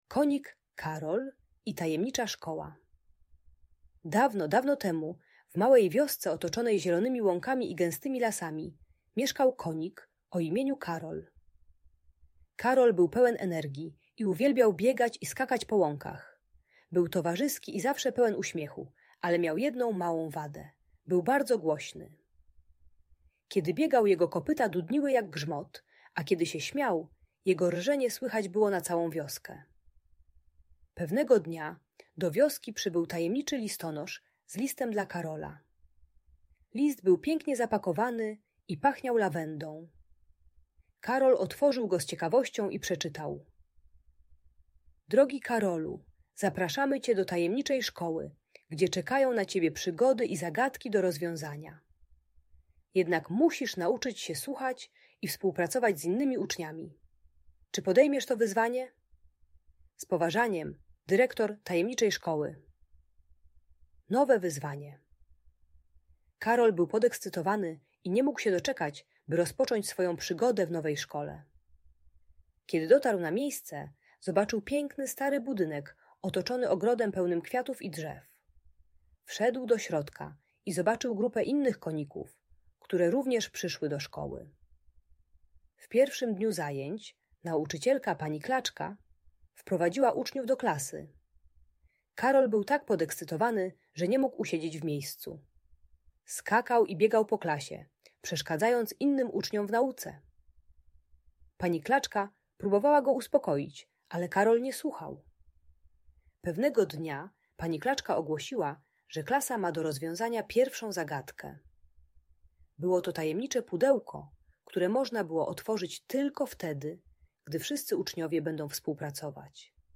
Historia Karola i Tajemniczej Szkoły - Audiobajka